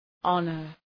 Προφορά
{‘ɒnər}